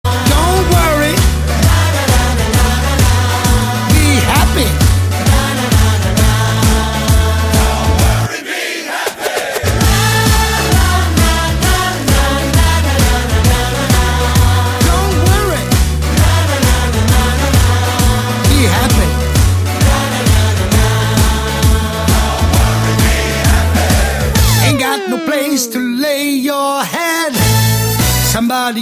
Gattung: Moderner Einzeltitel
Besetzung: Blasorchester
fetzige Version